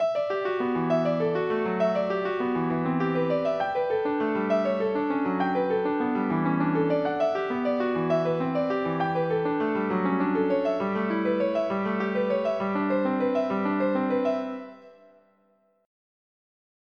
MIDI Music File